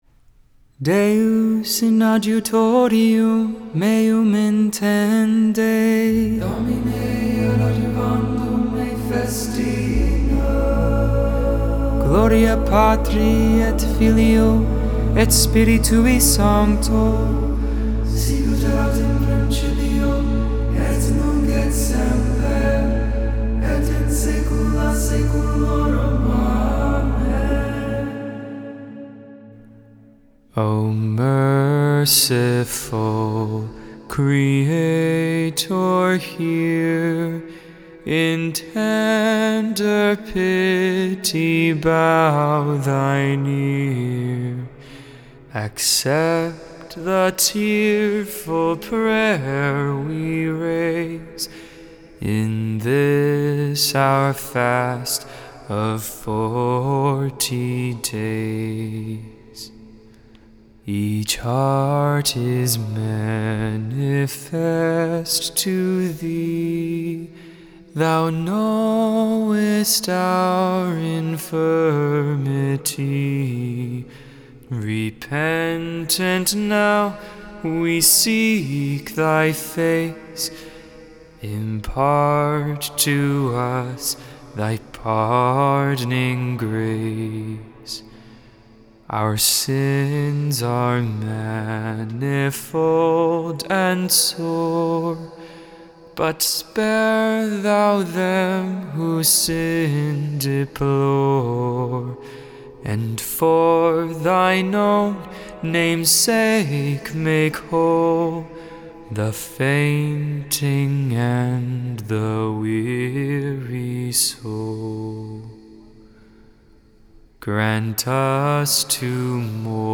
3.10.22 Vespers, Thursday Evening Prayer